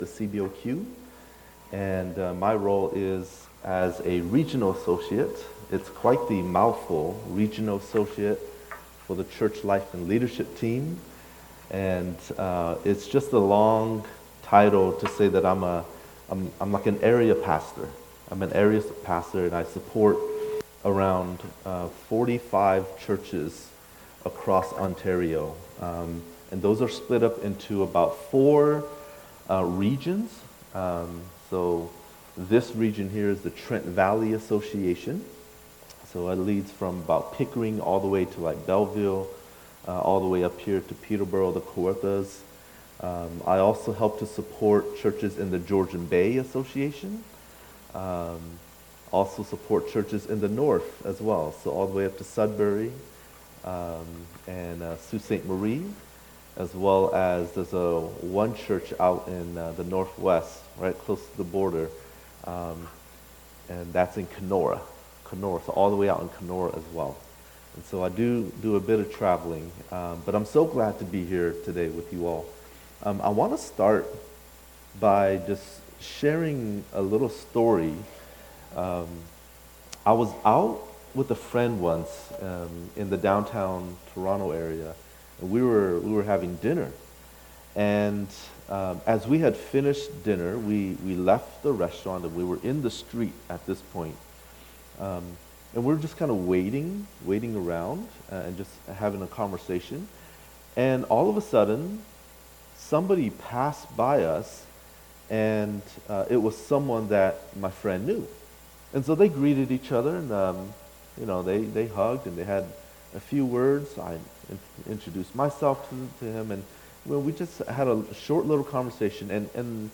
Sermons | Edmison Heights Baptist
EHBC's 61st Anniversary of ministry serving the Edmison Heights Community in the North part of Peterbourough.